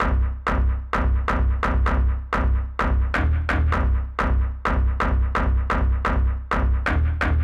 INT Stab Riff C-Eb.wav